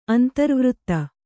♪ antarvřtta